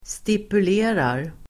Ladda ner uttalet
Folkets service: stipulera stipulera verb, stipulate Grammatikkommentar: A/x & y/att + SATS Uttal: [stipul'e:rar] Böjningar: stipulerade, stipulerat, stipulera, stipulerar Definition: bestämma, fastställa